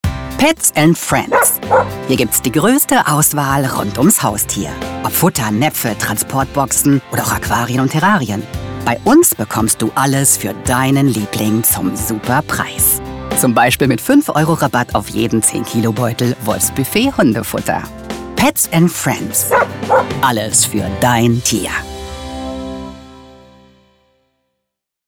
Charakterstimme, erregt Aufmerksamkeit, samtig-tief, einfĂŒhlsam, innovativ, glaubhaft, hohe Wiedererkennung, TV-Werbung, Audioguide, Doku / Off, E-Learning, Feature, Spiele, Funkspot, Imagefilm, Meditation, Nachrichten, ErklĂ€rfilm, Promotion, Sachtext, Trailer, Station-Voice, VoiceOver, Tutorials
Sprechprobe: Sonstiges (Muttersprache):
Character voice, attracts attention, velvety-deep, sensitive, innovative, credible, high recognition, TV commercial, audio guide, documentary / off-camera, e-learning, feature, games, radio spot, image film, meditation, news, explanatory film, promotion, factual text, trailer, station voice, voiceover, tutorials